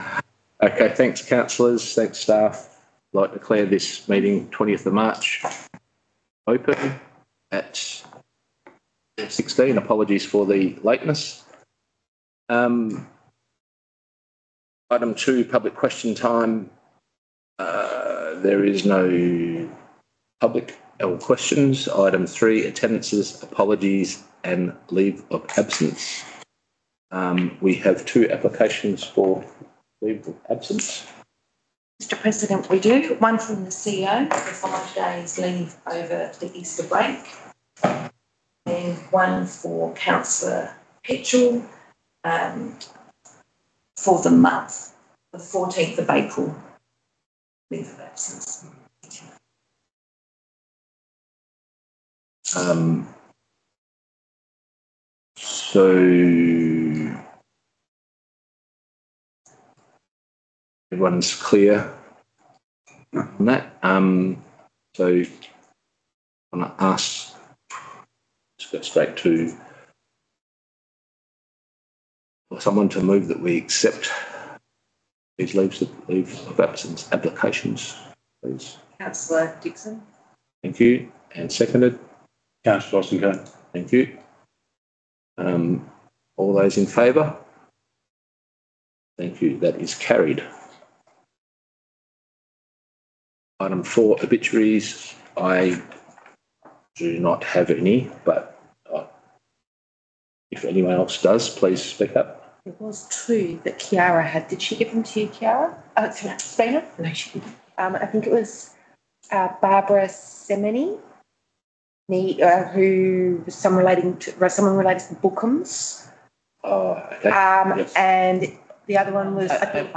Ordinary Council Meeting » Shire of Wyalkatchem
Location: Shire of Wyalkatchem Council Chambers